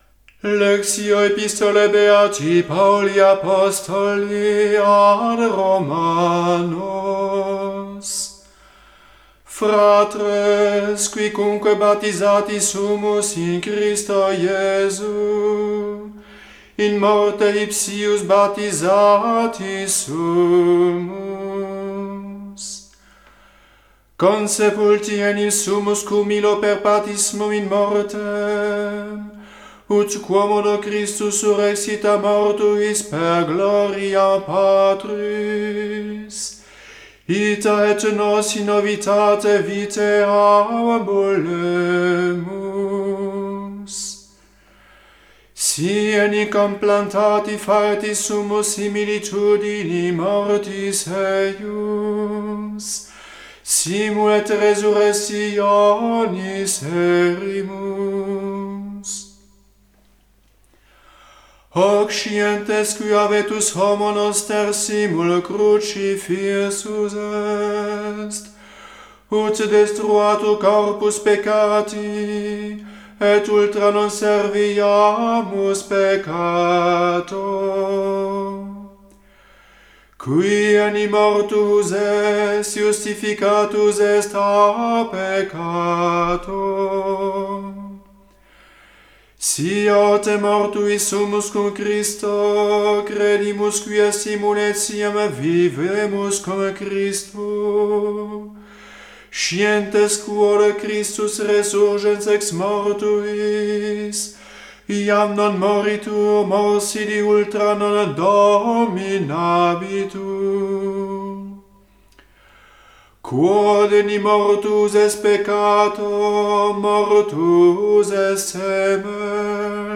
Epistola